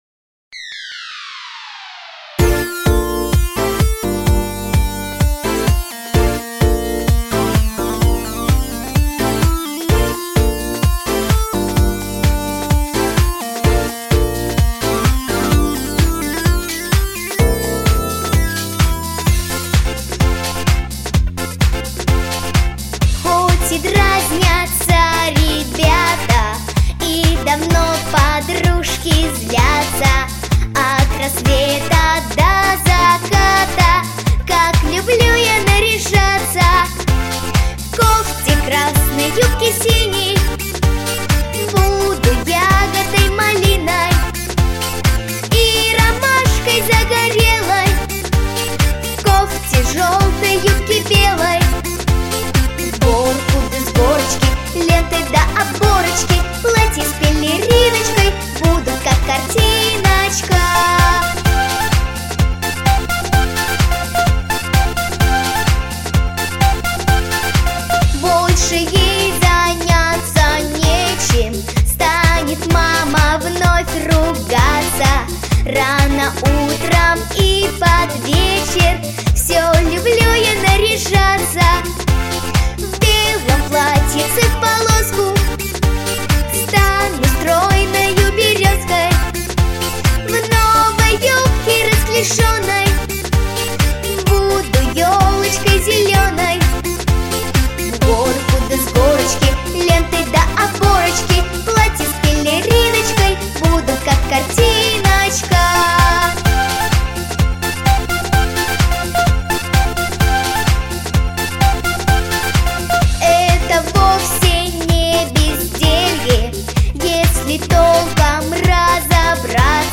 • Жанр: Детские песни
Детская песня
Детская вокально-эстрадная студия